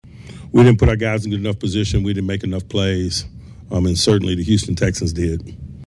A disappointed coach Mike Tomlin says it isn’t hard to see why the Steelers lost.